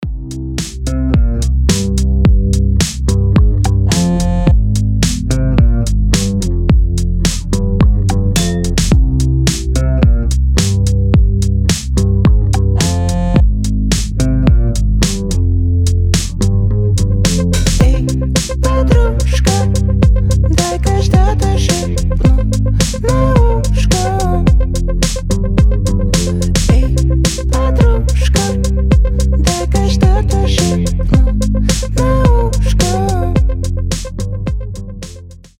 • Качество: 320, Stereo
indie pop
alternative